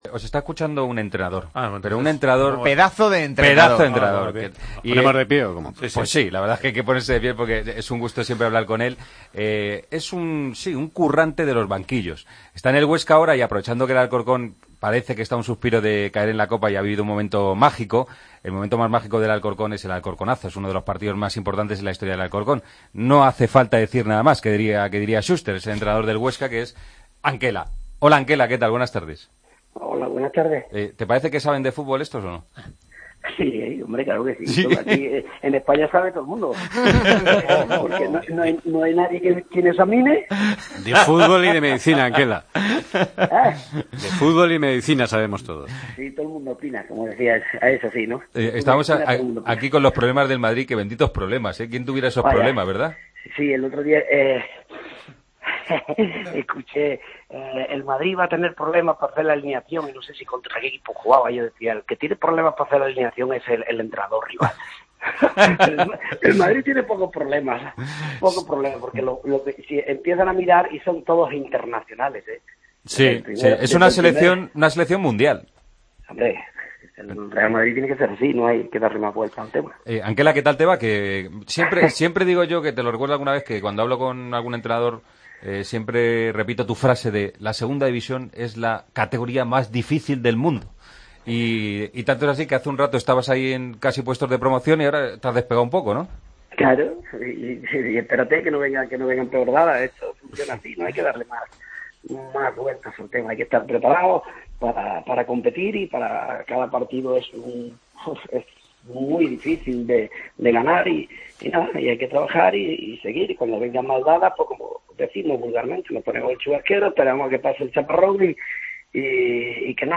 Hablamos con el entrenador del Huesca y exentrenador del Alcorcón: "Tardarán muchos años en repetirse el 'Alcorconazo', vino Dios y dijo ahora os toca a vosotros. En el fútbol nunca se puede decir que está visto para sentencia y hoy el Alcorcón va a competir, sin ninguna duda".